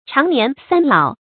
長年三老 注音： ㄔㄤˊ ㄋㄧㄢˊ ㄙㄢ ㄌㄠˇ 讀音讀法： 意思解釋： 古時指船工。